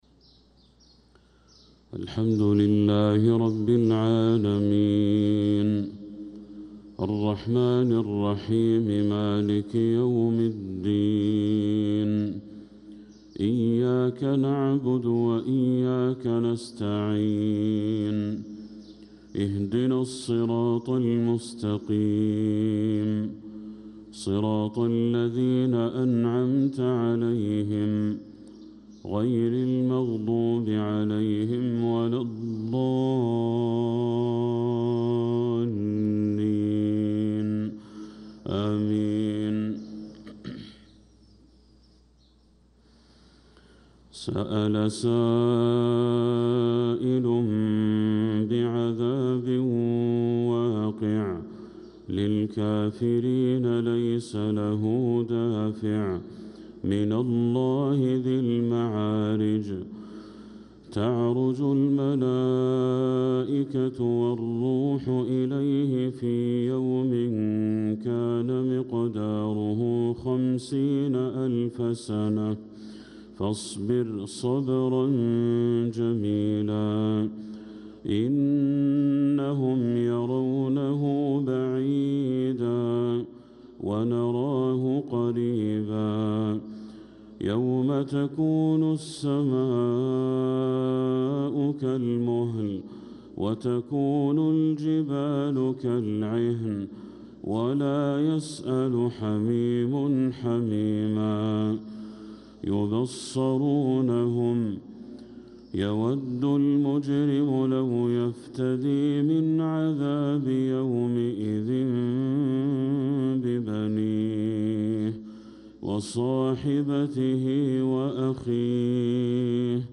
صلاة الفجر للقارئ بدر التركي 27 ربيع الآخر 1446 هـ
تِلَاوَات الْحَرَمَيْن .